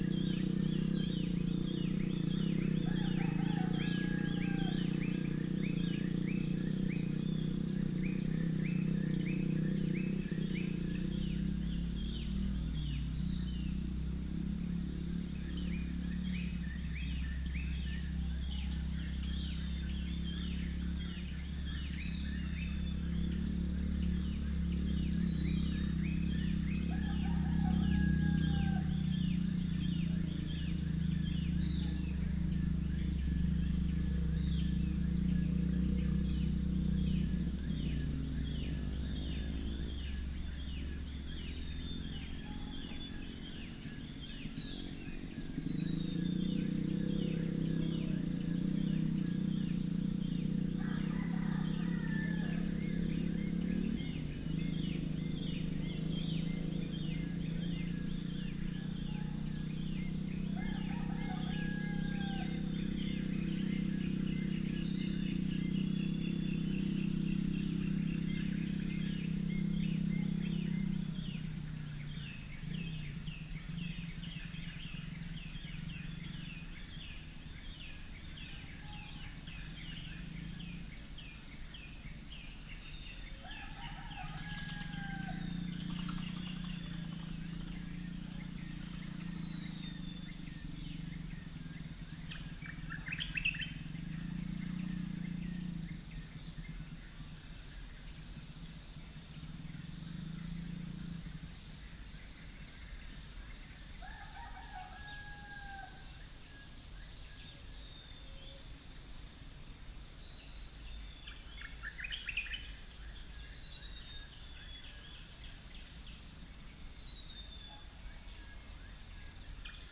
Upland plots dry season 2013
Centropus bengalensis
Gallus gallus domesticus
Orthotomus sericeus
Pycnonotus goiavier
Brachypodius atriceps
Copsychus saularis